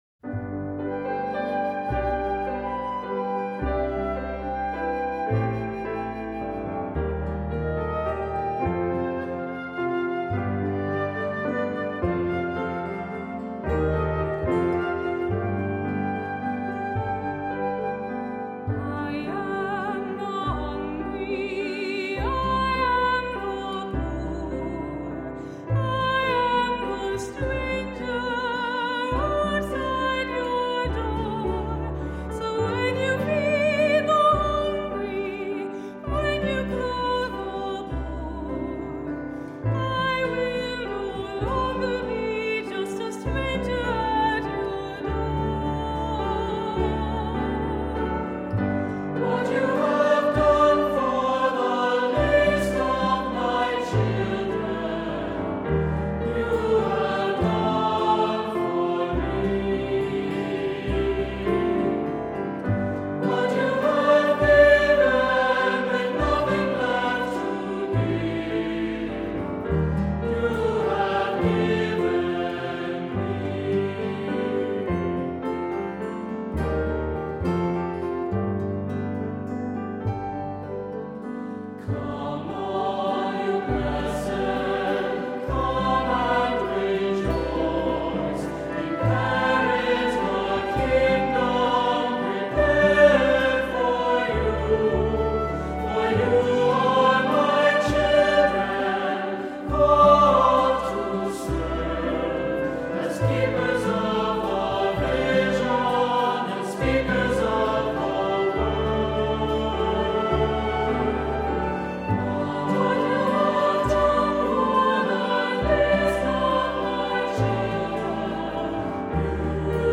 Accompaniment:      Keyboard
Music Category:      Christian
For cantor or soloist.